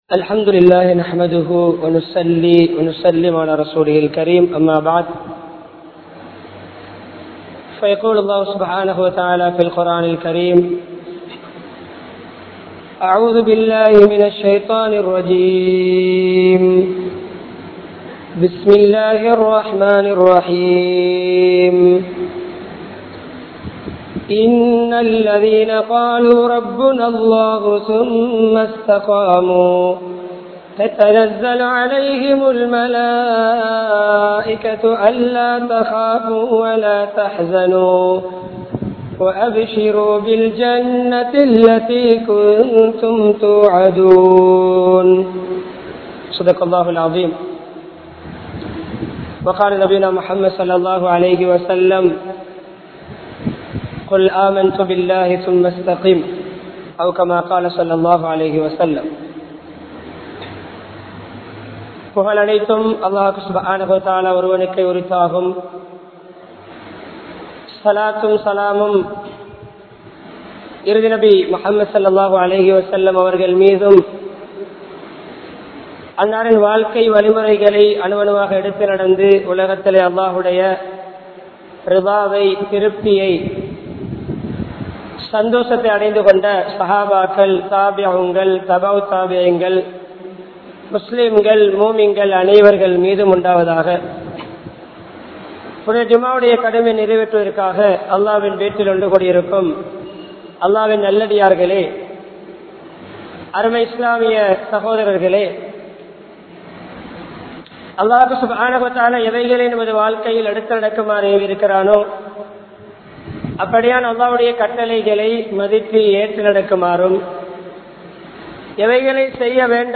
Ramalanil Pettra Thooimayai Paathuhaappoam (ரமழானில் பெற்ற தூய்மையை பாதுகாப்போம்) | Audio Bayans | All Ceylon Muslim Youth Community | Addalaichenai
Colombo 12, Umbitchi Jumua Masjidh